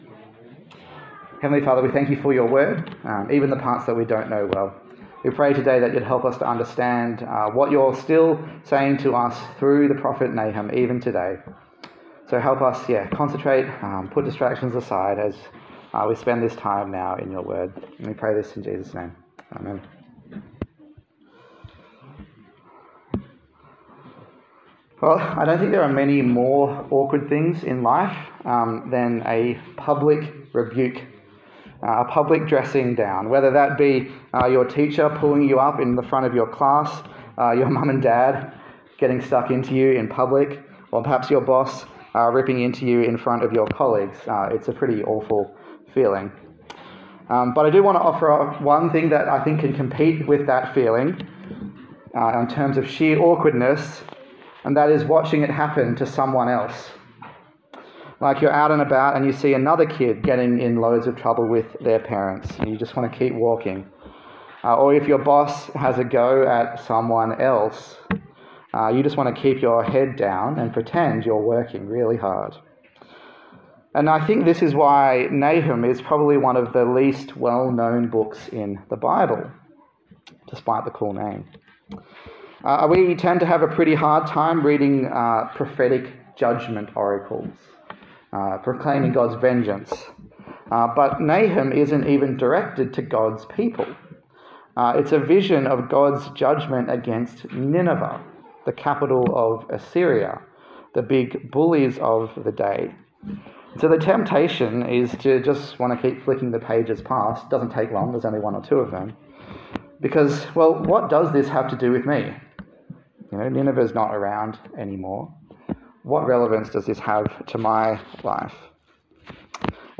Nahum Passage: Nahum Service Type: Sunday Morning